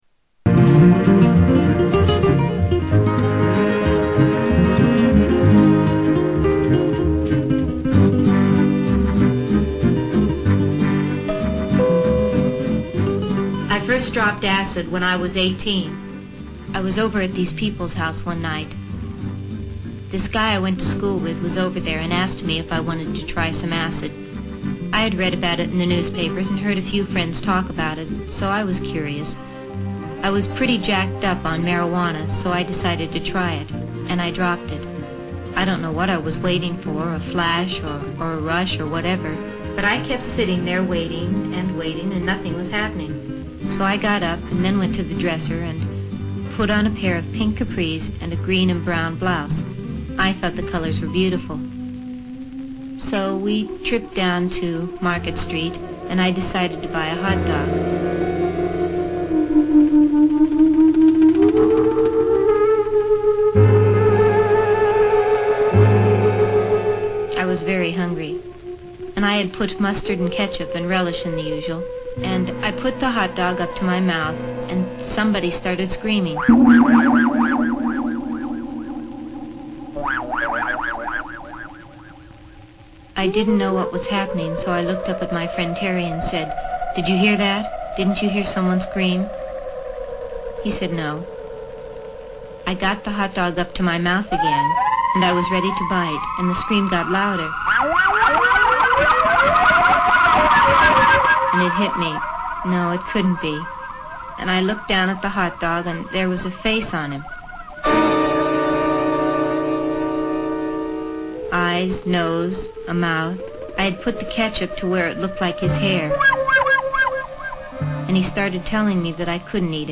1960s LSD Propaganda Film